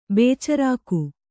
♪ bēcarāku